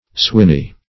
Search Result for " swinney" : The Collaborative International Dictionary of English v.0.48: Swinney \Swin"ney\, n. (Far.)